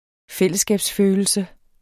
Udtale [ ˈfεlˀəsˌsgæˀbs- ]